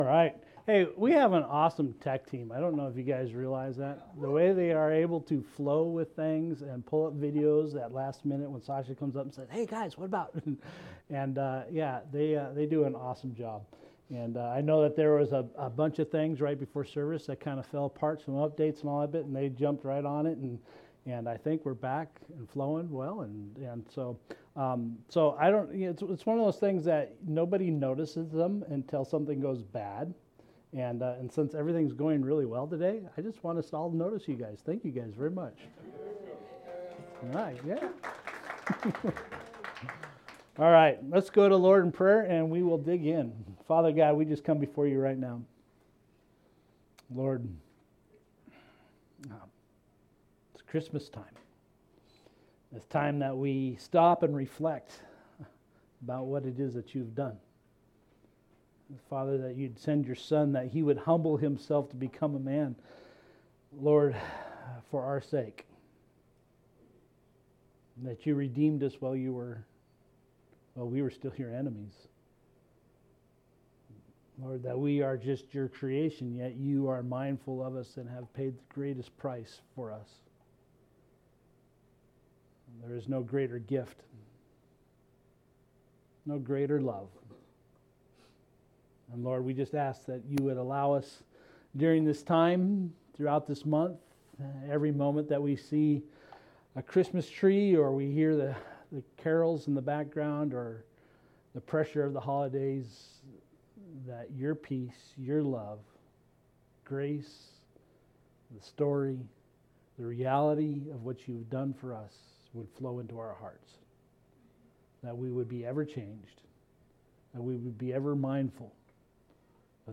Sermons | Explore Church